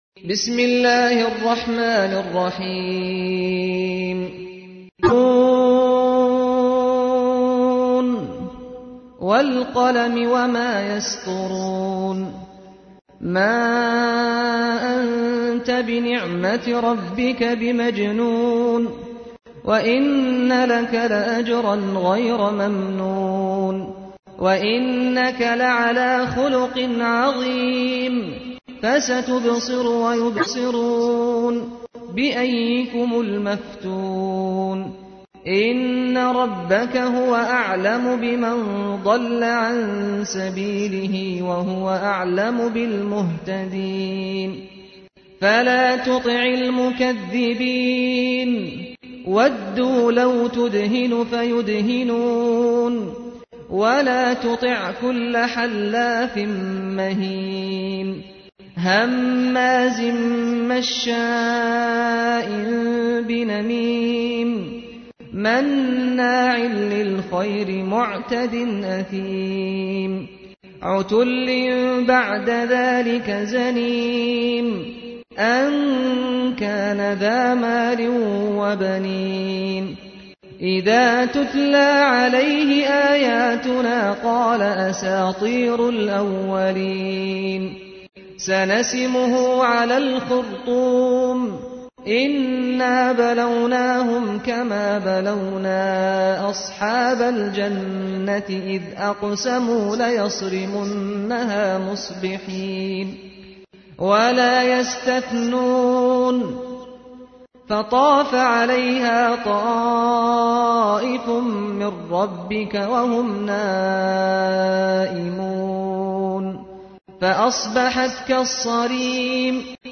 تحميل : 68. سورة القلم / القارئ سعد الغامدي / القرآن الكريم / موقع يا حسين